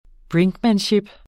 Udtale [ ˈbɹeŋgmanˌɕib ]